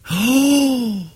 Man Gasp 10